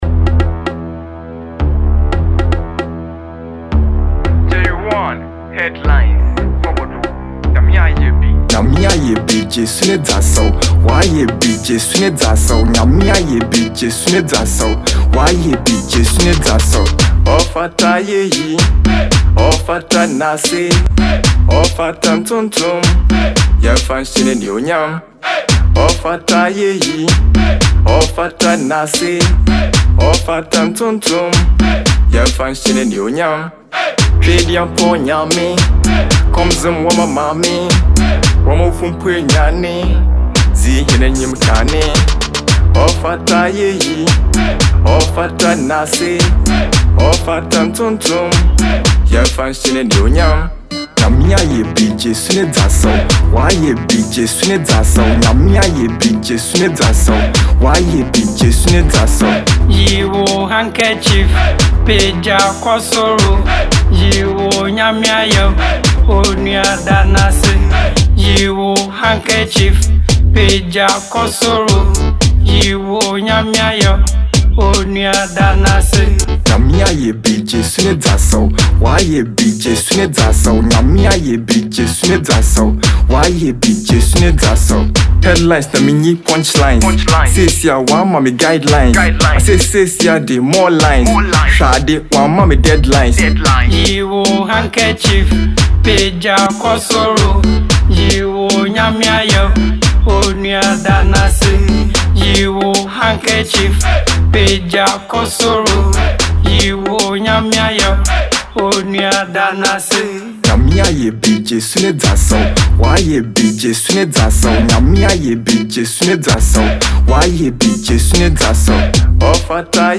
Fante rapper
Urban Gospel